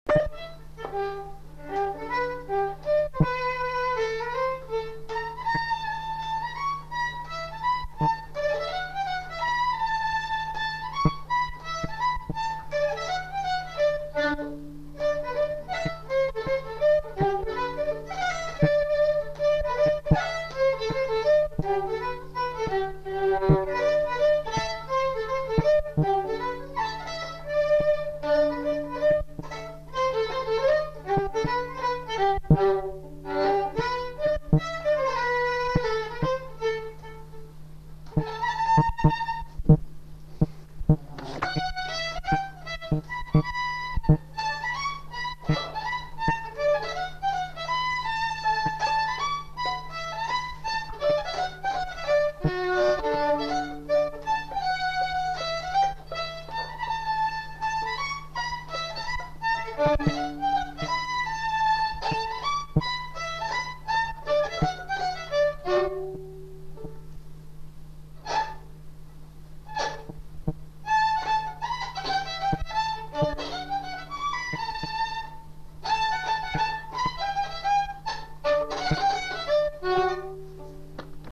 Lieu : Saint-Michel-de-Castelnau
Genre : morceau instrumental
Instrument de musique : violon
Danse : mazurka